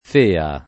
fea [ f % a ] imperf. (poet.) di fare